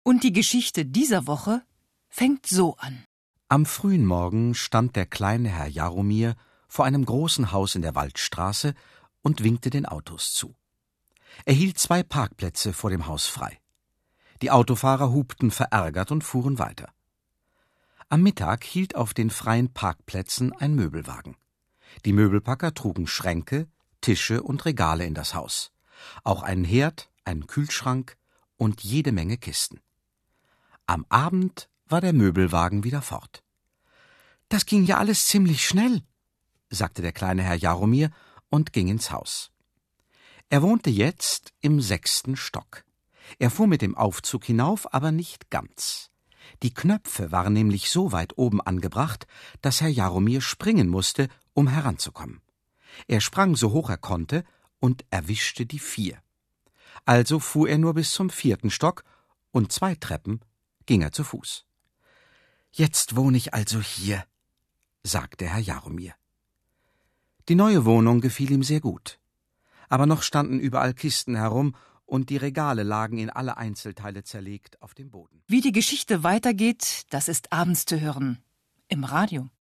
Zu dumm, dass er sich nicht seine Hausnummer gemerkt hat … (Gelesen von Gerd Wameling im Ohrenbär, RBB/WDR/NDR)